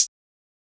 Closed Hats
Hihat (Hype).wav